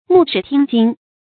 牧豕聽經 注音： ㄇㄨˋ ㄕㄧˇ ㄊㄧㄥ ㄐㄧㄥ 讀音讀法： 意思解釋： 一面放豬，一面聽講。比喻求學努力。